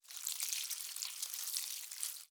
SFX_WateringPlants_02.wav